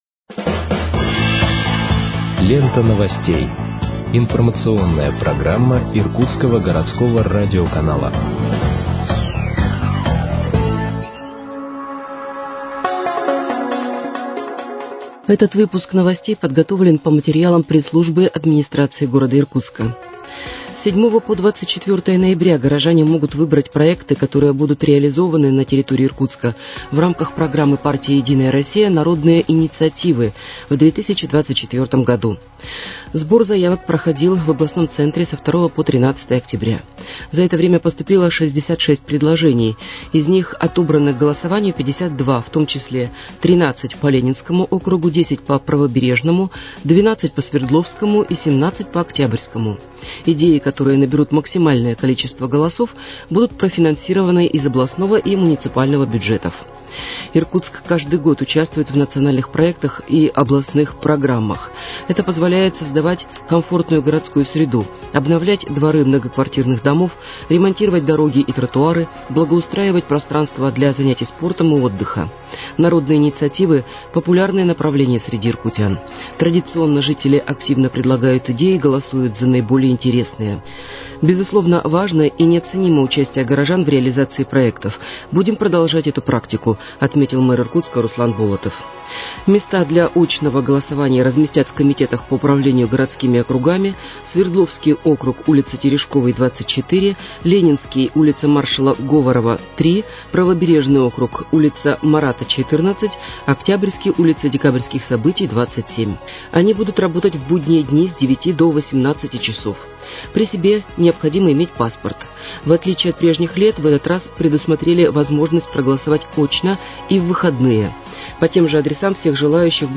Выпуск новостей в подкастах газеты «Иркутск» от 03.11.2023 № 2